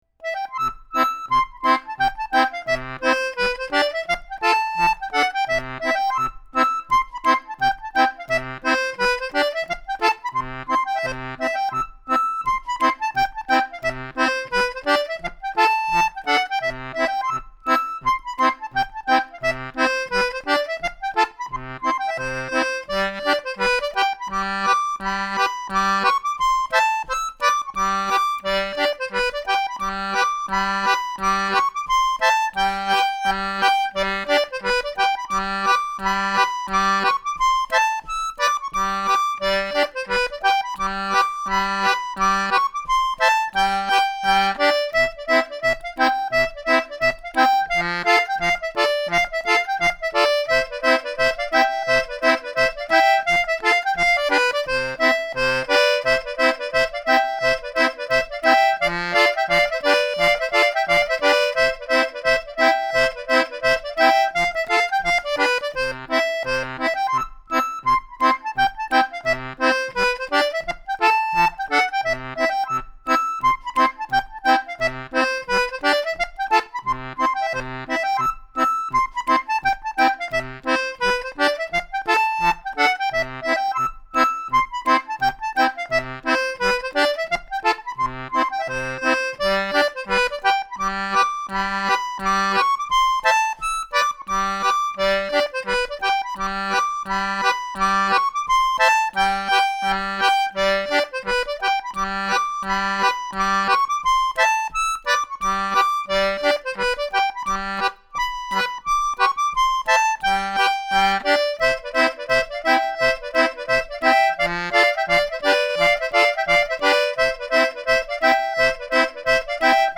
Review of Wolverton C/G and G/D Anglo Concertinas
The tone does reveal its accordion reeds but as you can hear from the recordings it is still very acceptable.
bluebell_polka.mp3